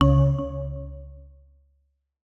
UIClick_Clean Tonal Button 02.wav